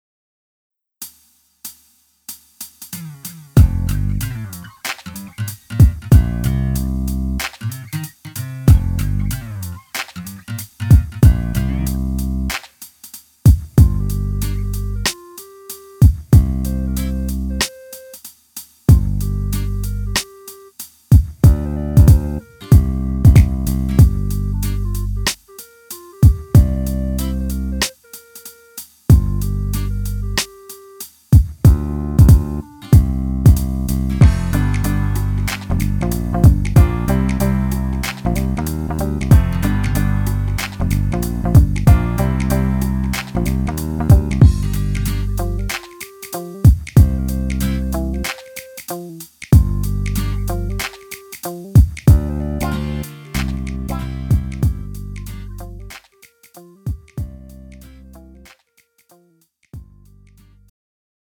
음정 -1키 3:47
장르 가요 구분 Pro MR